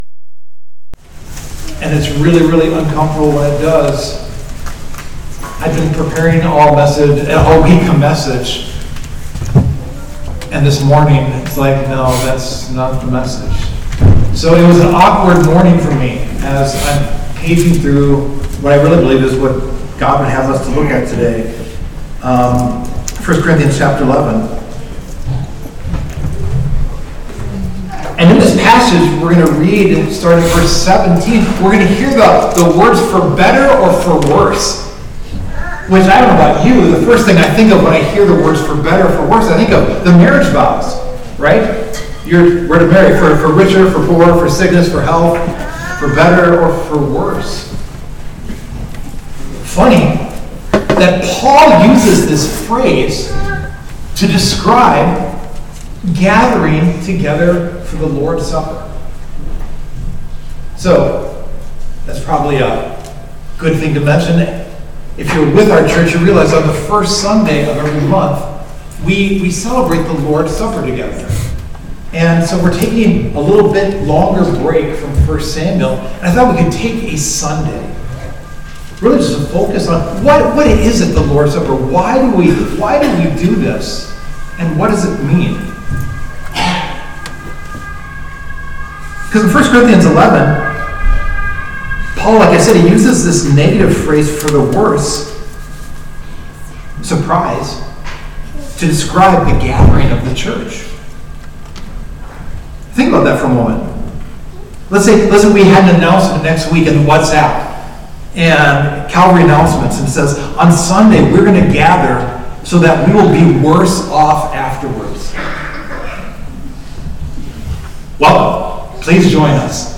1 Samuel 25 – Calvary Stockholm Sermons – Podcast – Podtail